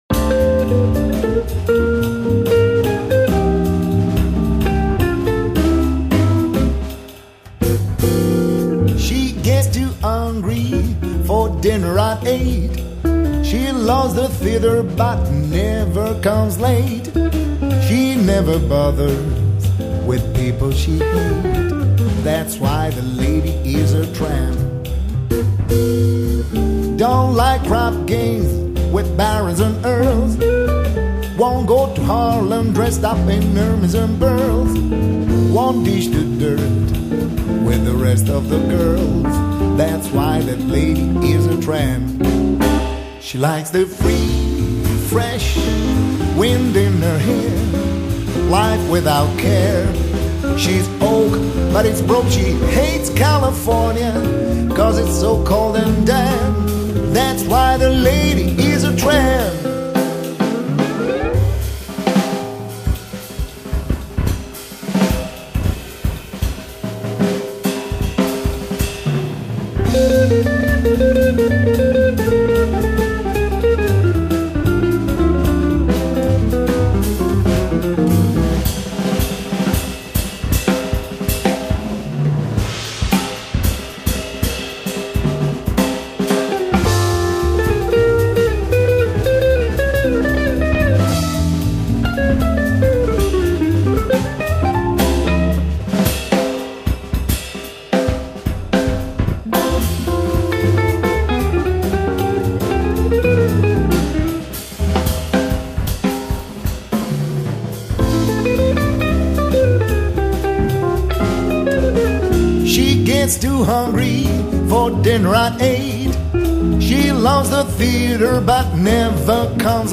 类别： 爵士
主奏乐器：钢琴